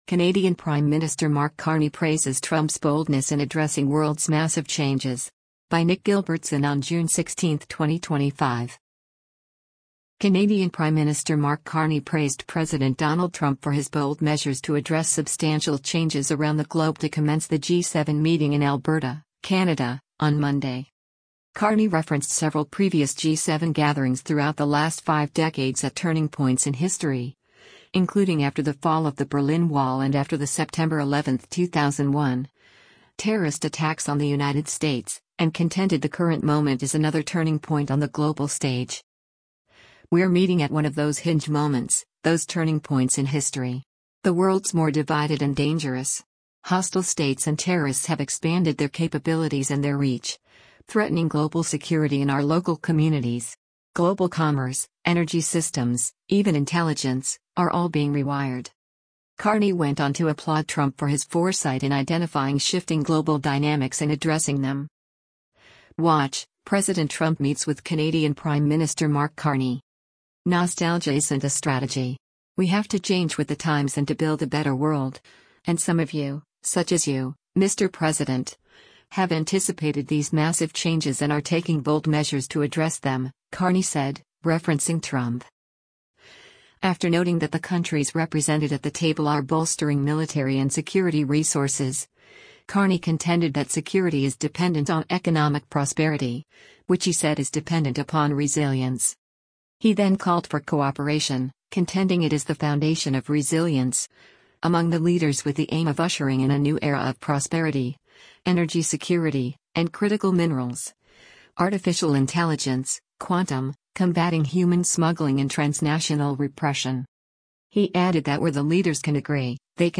WATCH — President Trump Meets with Canadian PM Mark Carney:
Trump and Carney spoke to reporters as the leaders commenced a private bilateral meeting before the official welcome ceremony and the first session between all seven leaders.